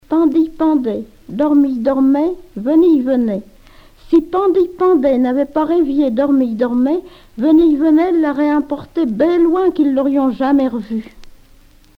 enfantine : comptine
Genre brève